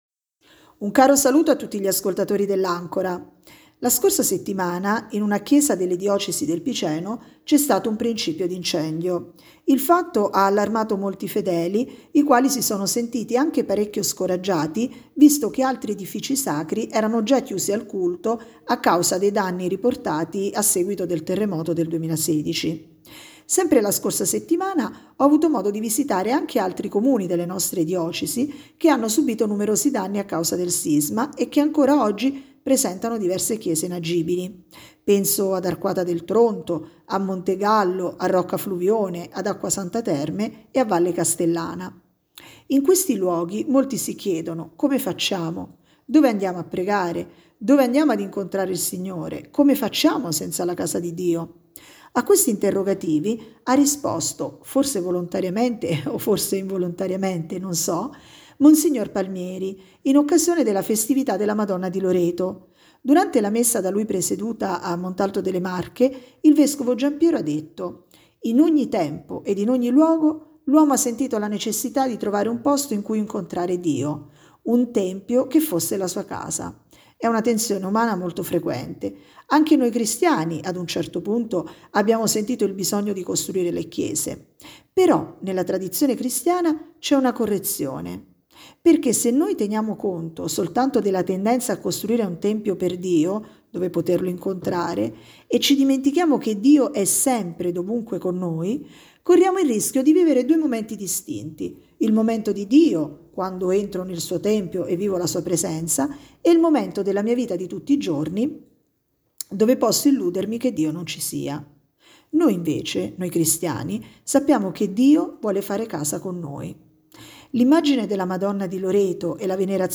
Se vuoi scoprire cosa ha risposto il nostro vescovo Gianpiero Palmieri, ascolta qui il podcast: